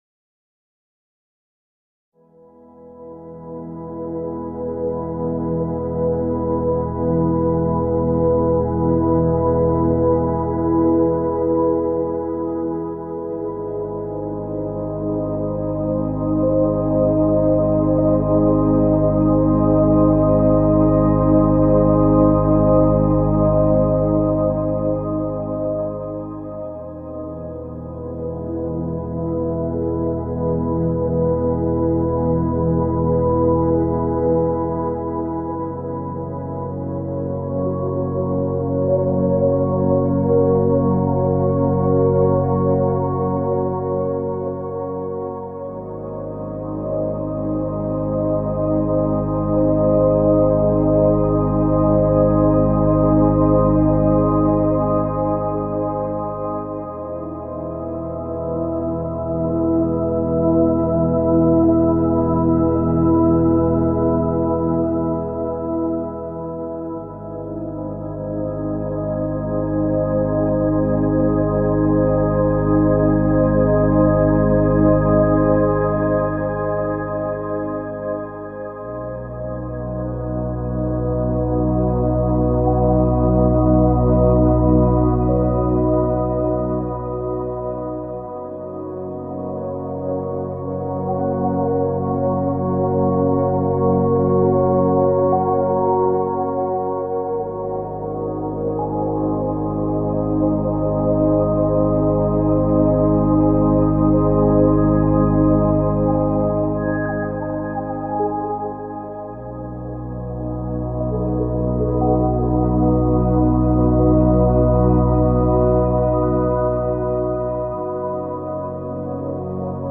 „Himmel & Erden“-MeditationEine geführte Meditation, die Dich erdet und zeitgleich nach oben hin öffnet. Du lernst, wie du Dich mit der kraftvollen Energie der Erde und zugleich mit den universellen Energien, der göttlichen Quelle oder auch deinem höheren Selbst verbinden kannst.